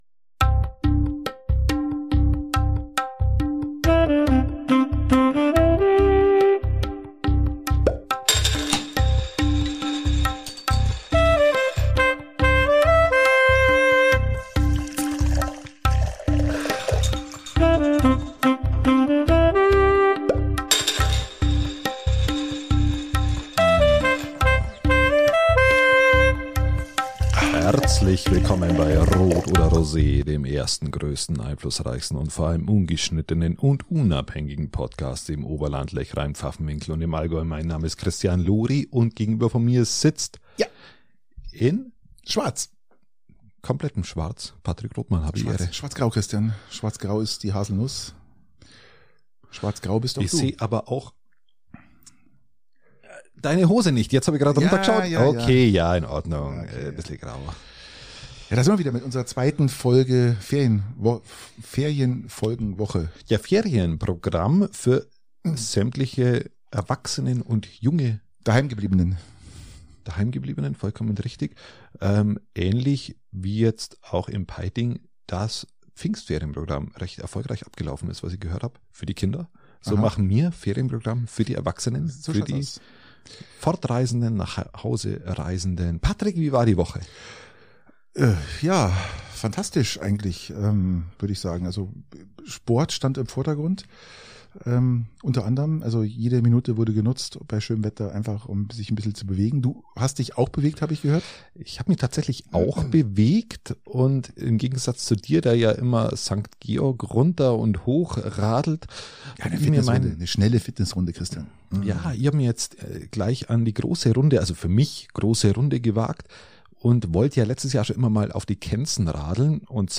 Ungeschnittenen und unabhängig!